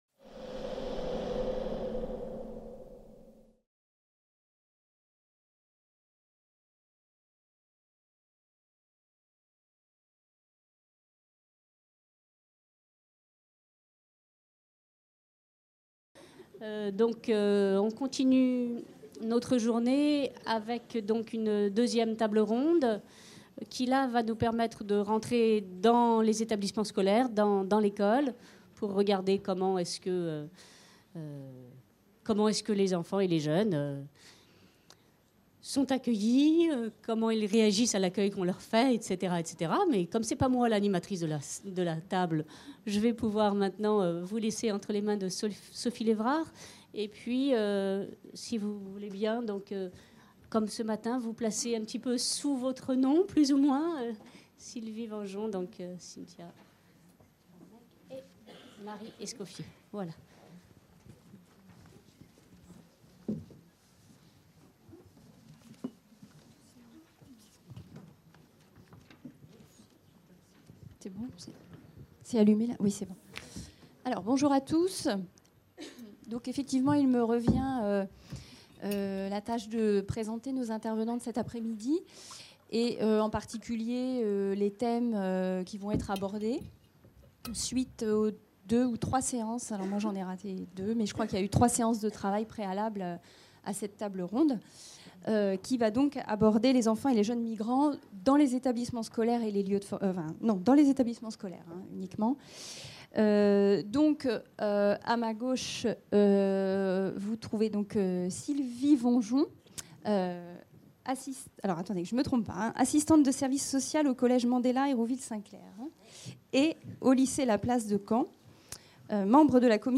CIRNEF19 | 03 - Table ronde_2 | Canal U